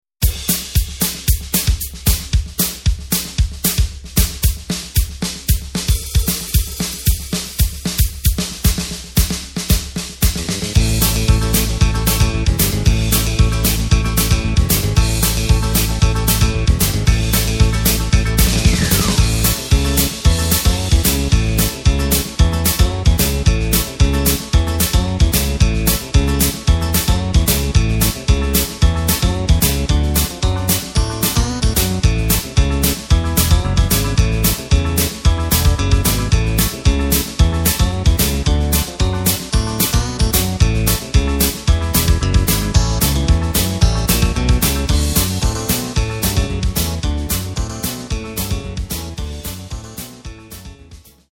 Takt:          4/4
Tonart:            C
Playback mp3 Demo